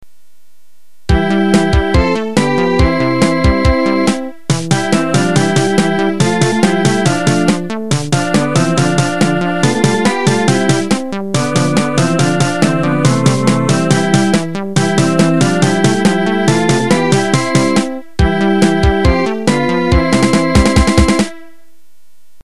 私の Domino + MS-MIDI（WindowsXPに標準で組み込まれている音）環境では、
09_MilkSongBGM.mp3